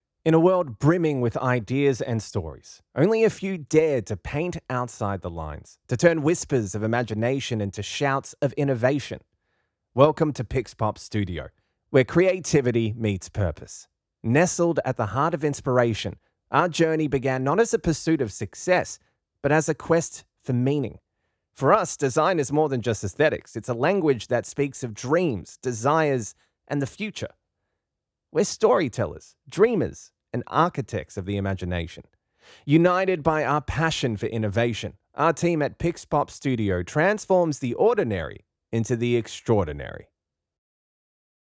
E=learning, Corporate & Industrial Voice Overs
Adult (30-50) | Yng Adult (18-29)